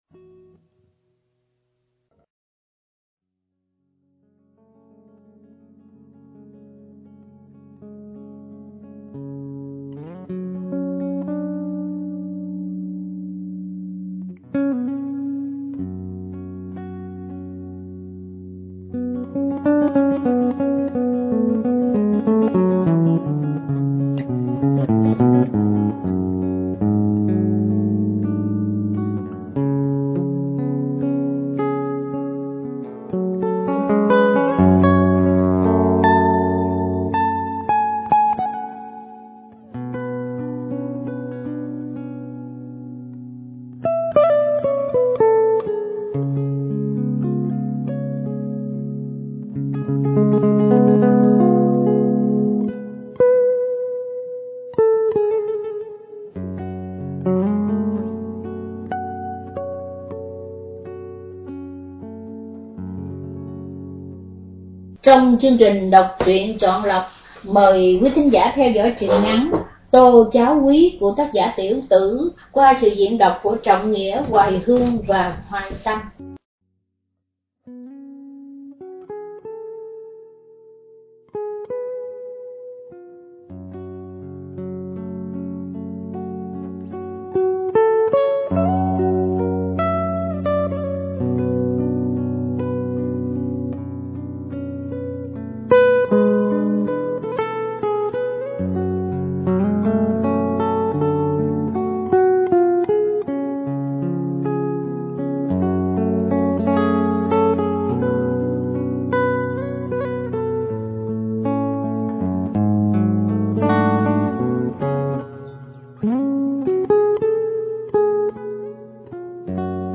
Đọc Truyện Chọn Lọc ” Truyện Ngắn ” Tô Cháo Huyết – Tác Giả Tiểu Tử – Radio Tiếng Nước Tôi San Diego